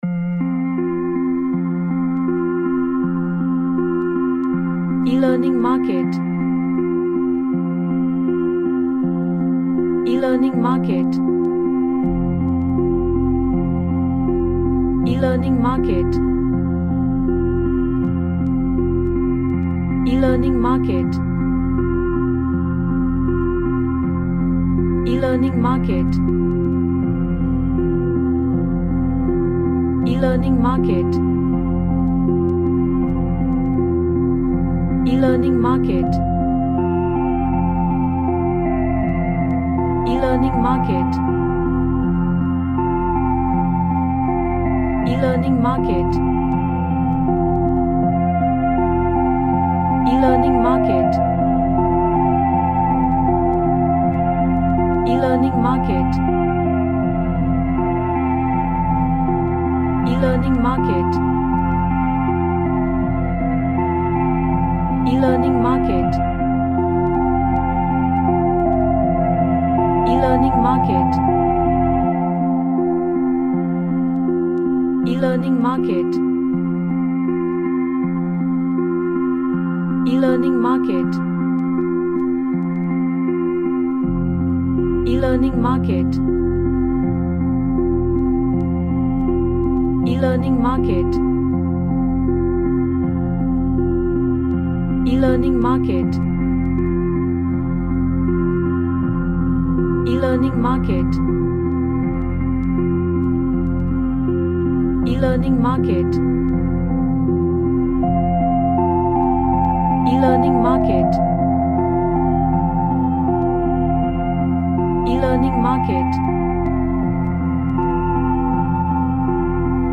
A mystical type of ambience
Relaxation / Meditation